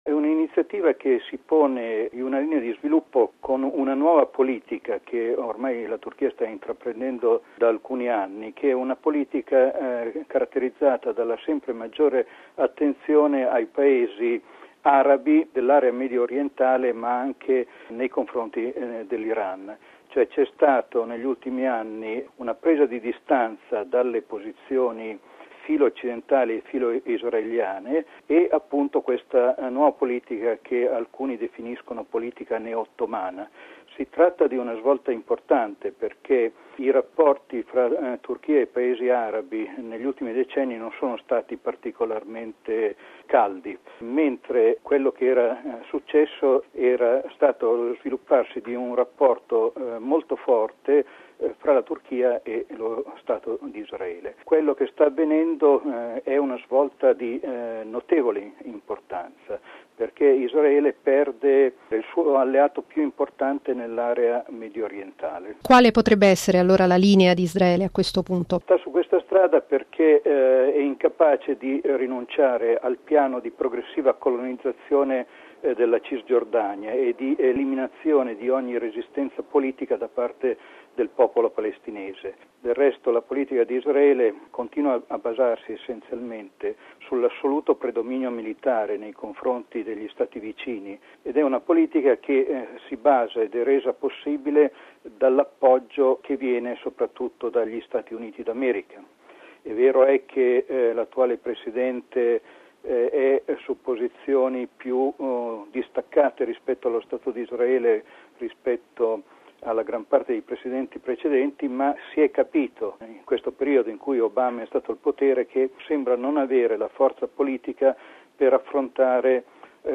intervistato